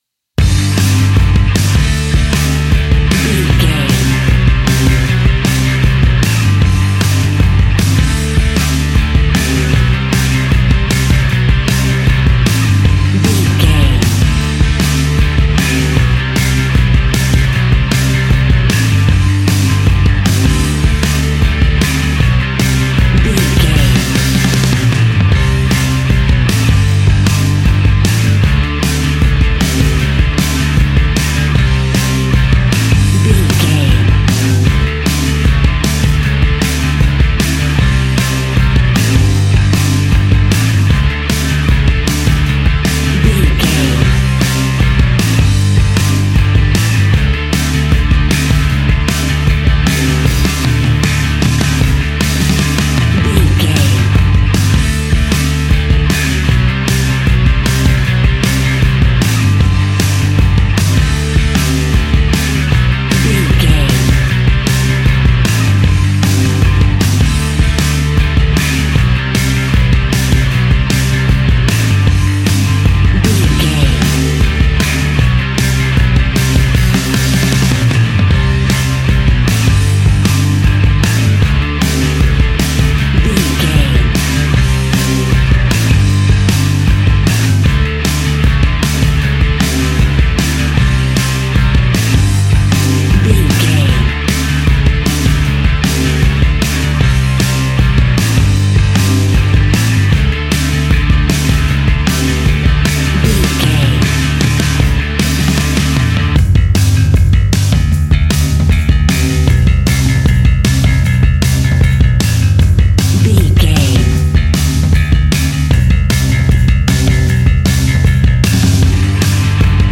Epic / Action
Fast paced
Ionian/Major
hard rock
distortion
punk metal
instrumentals
Rock Bass
Rock Drums
distorted guitars
hammond organ